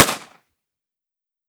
38 SPL Revolver - Gunshot B 001.wav